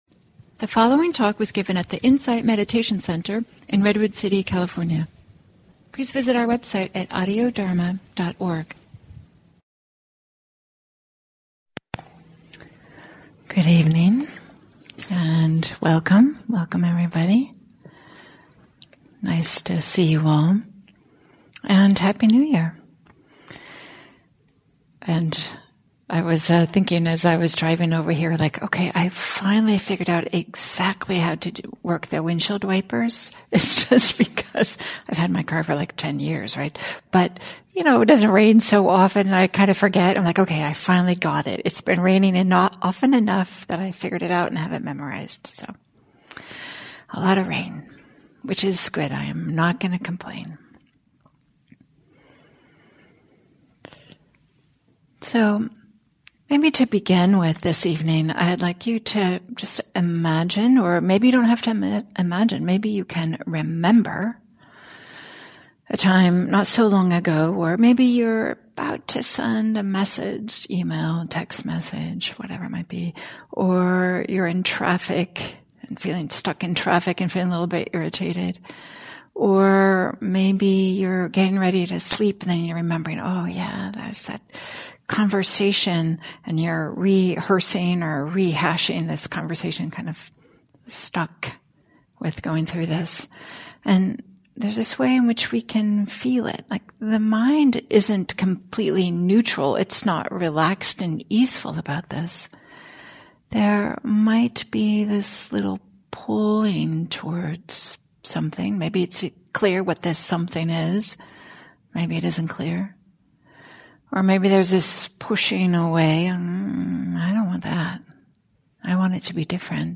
at the Insight Meditation Center in Redwood City, CA.